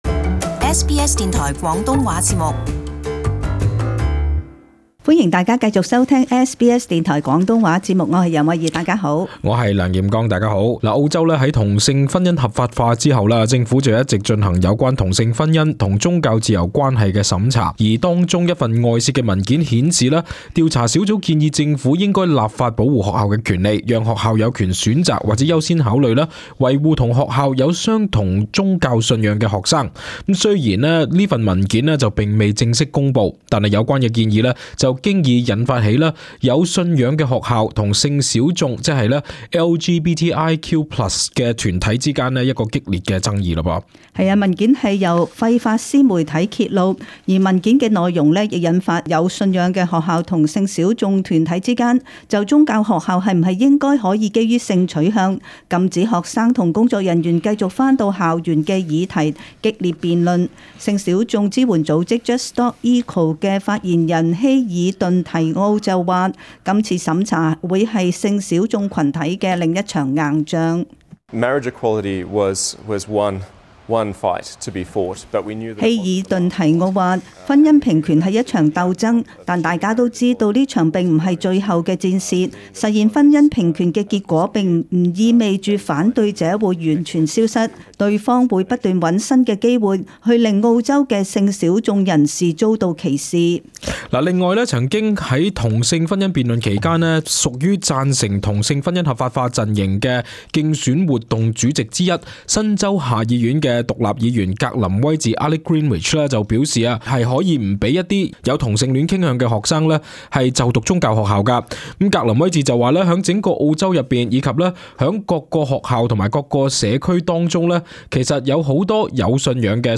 【時事報導】同性婚姻與宗教自由審查報告外洩